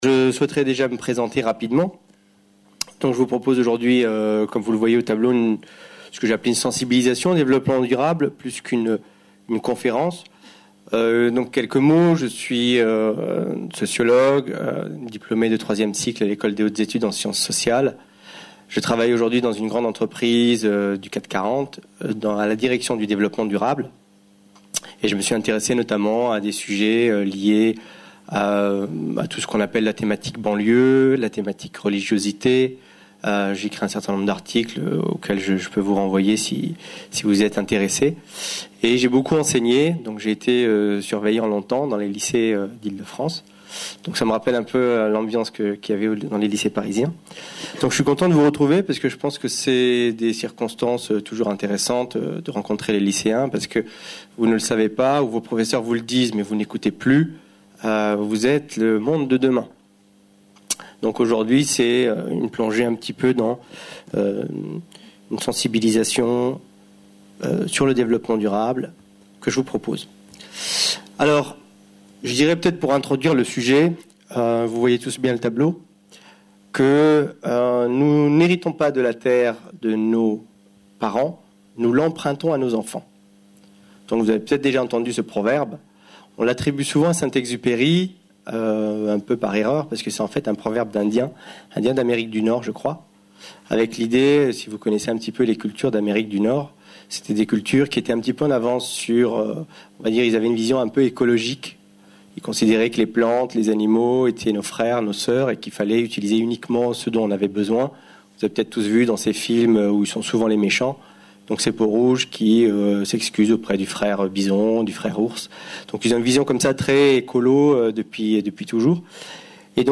Une conférence de l'UTLS au Lycée L’EUROPE ET LE DEVELOPPEMENT DURABLE